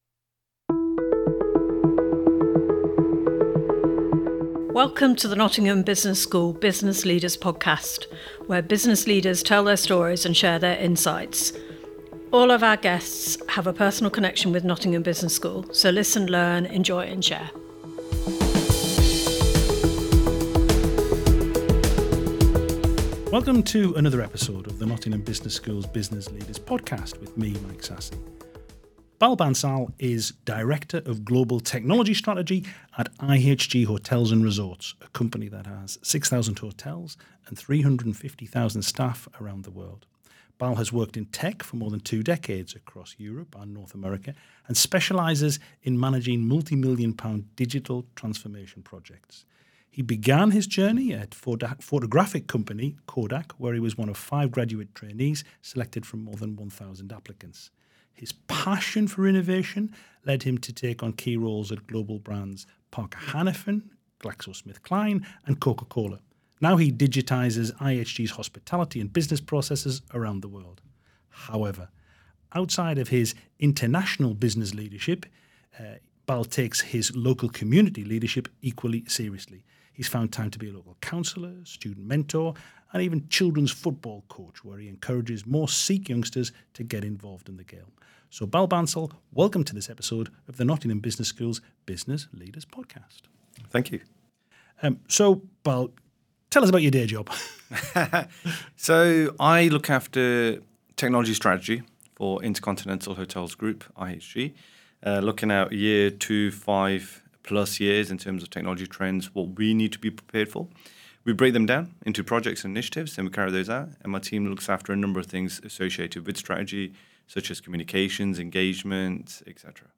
in conversation with business leaders